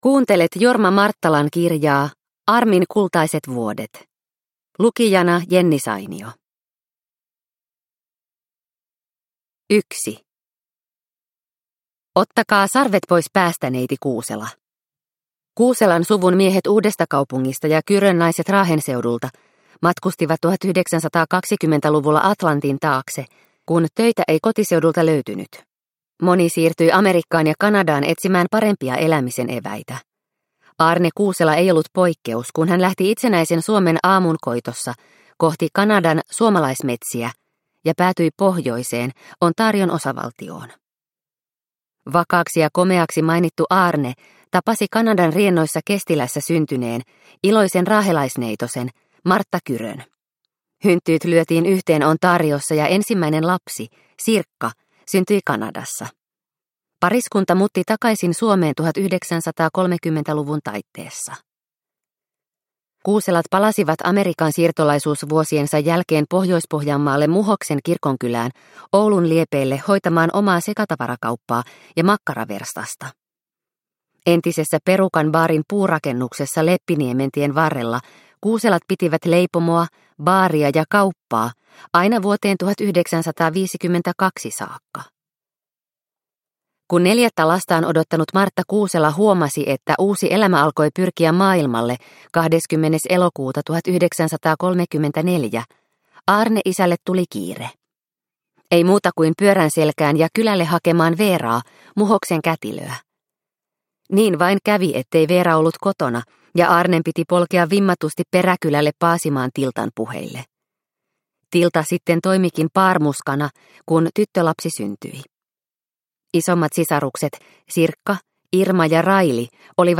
Armin kultaiset vuodet – Ljudbok – Laddas ner